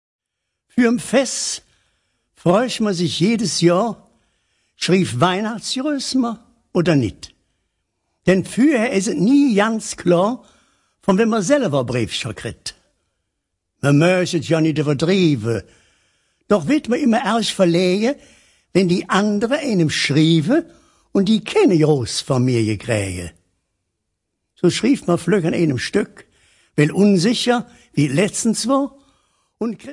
Weihnachtsleedcher und Verzällcher in Kölscher Mundart